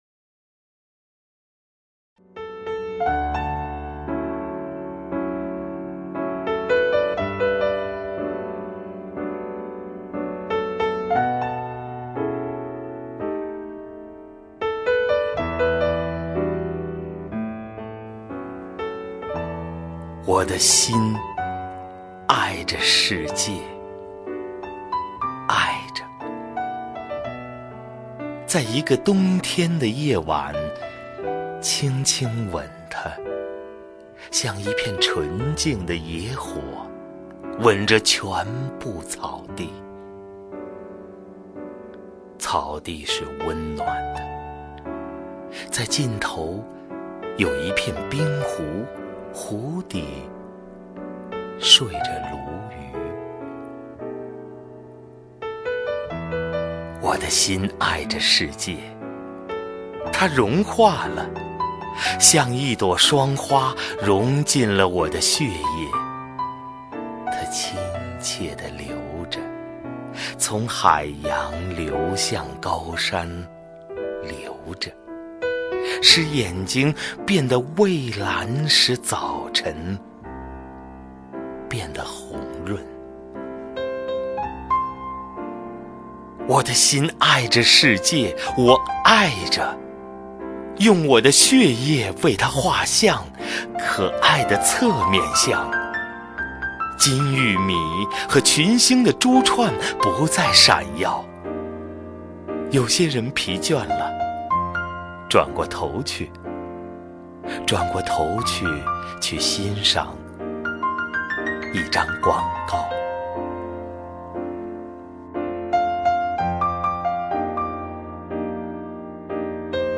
首页 视听 名家朗诵欣赏 赵屹鸥
赵屹鸥朗诵：《我的心爱着世界》(顾城)